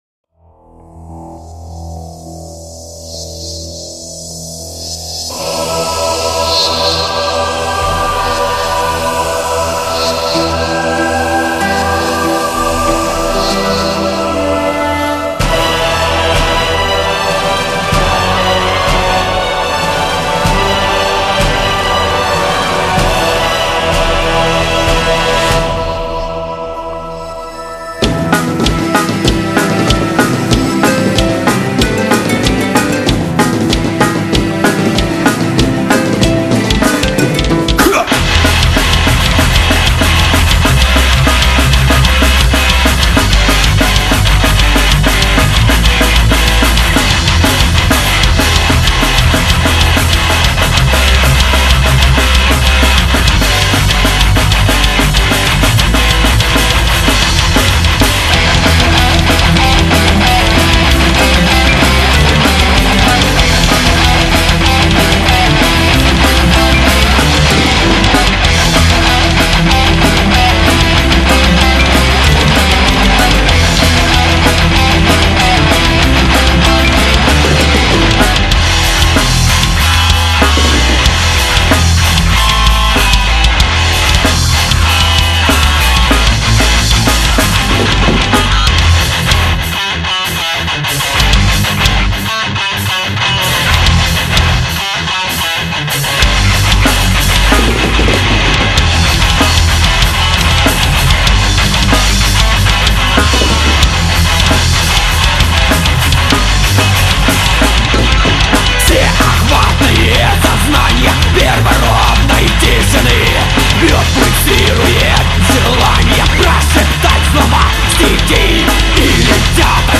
symphonic pagan metal